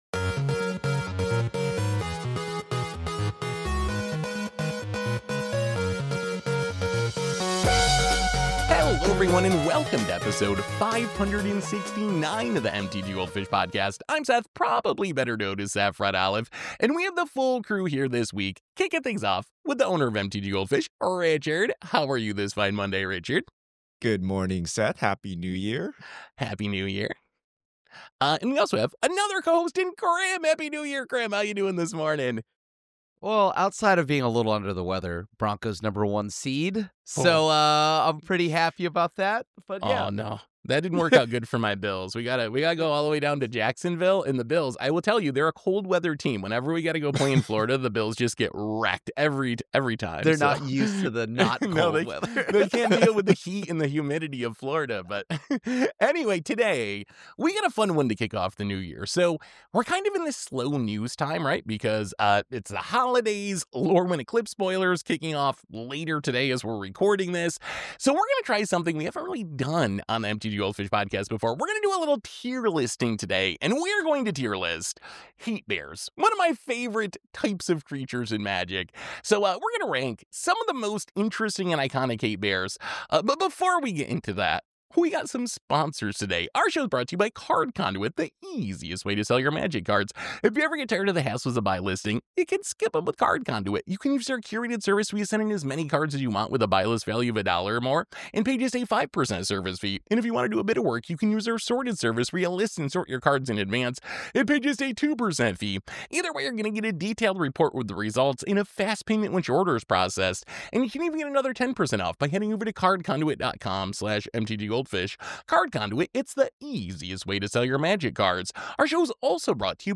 Every week get an in-depth discussion behind the latest news in Magic the Gathering, focusing on finance, competitive deck building and budget brewing.